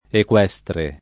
equestre [ ek U$S tre ]